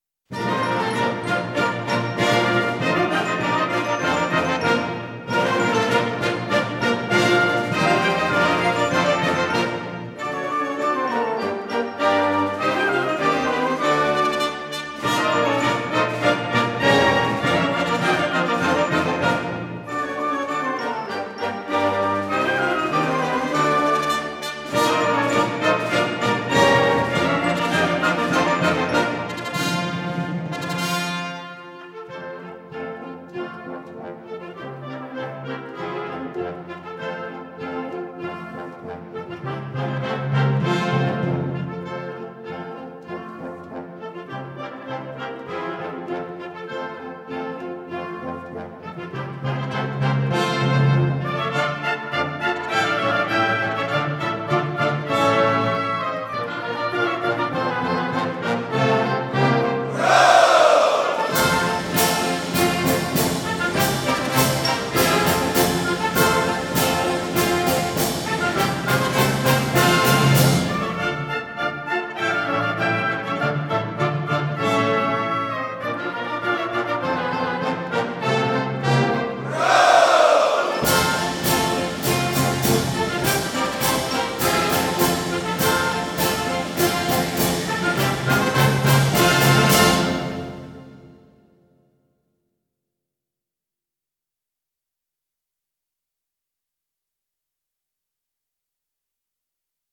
Этот марш действительно - марш Лейб-гвардии Егерского полка.